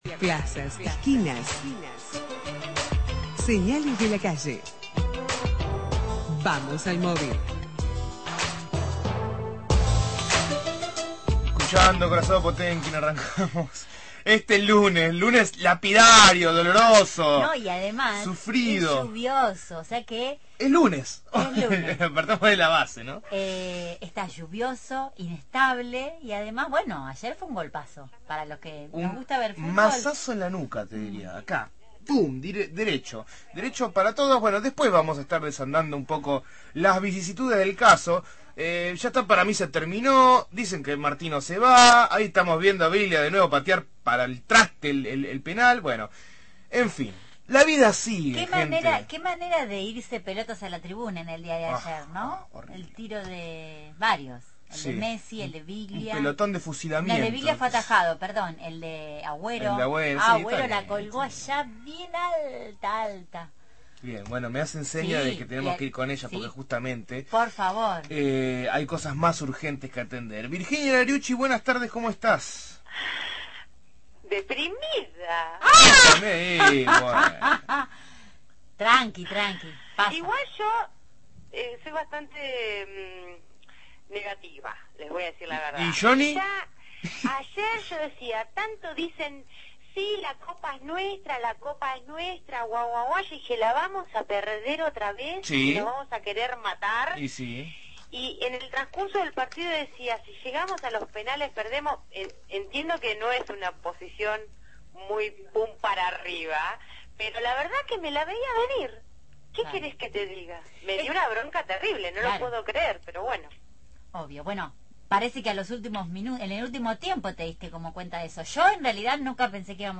MÓVIL/ Banco Alimentario busca voluntarios para cosecha anual de kiwi – Radio Universidad